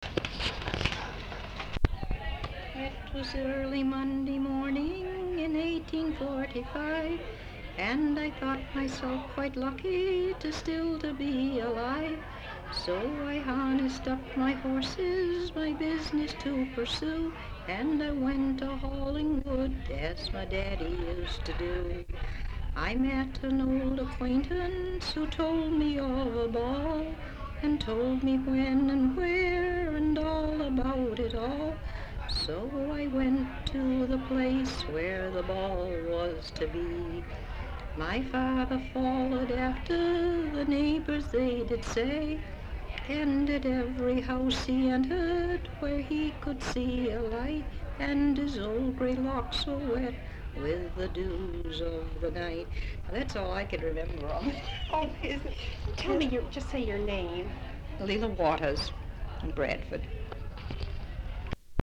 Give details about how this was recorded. sound tape reel (analog)